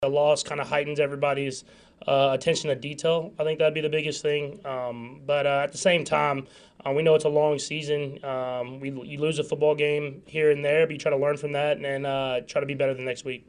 KC Patrick Mahomes says they are hyper-focused